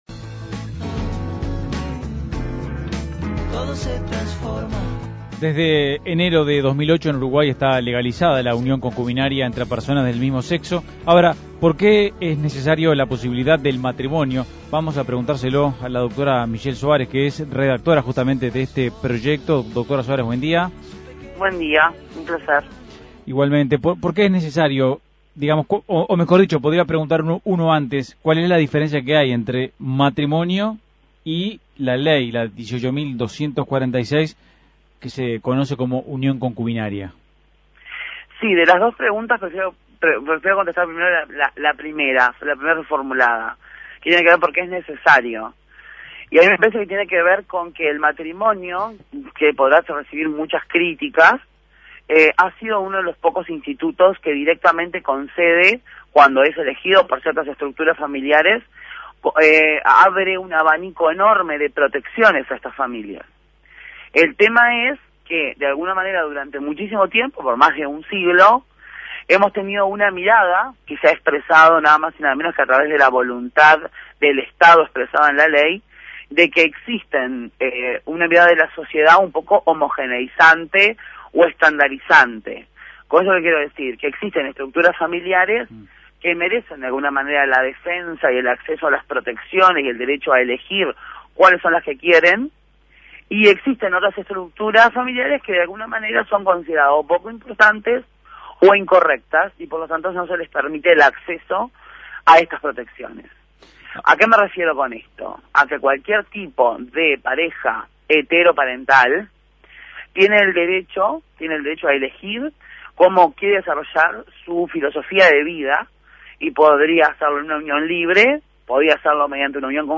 Entrevista a Michelle Suárez y Sebastián Sabini